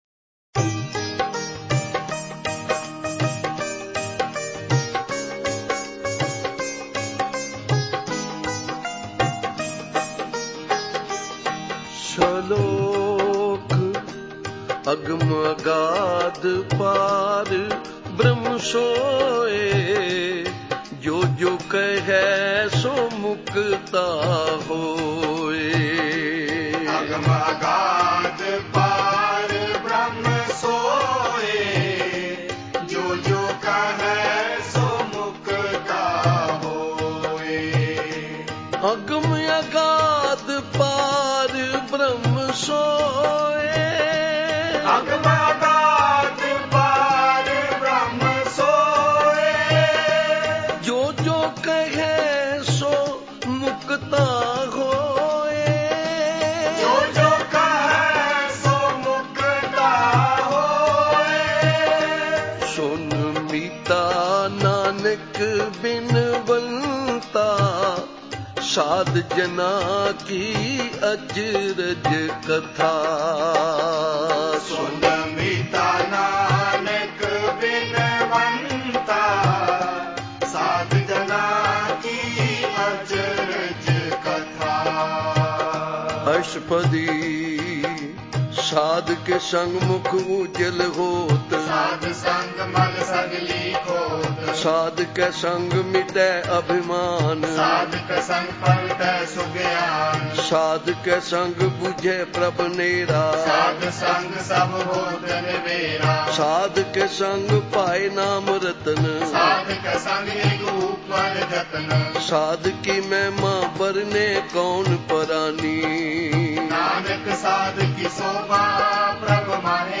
Genre: Gurbani Ucharan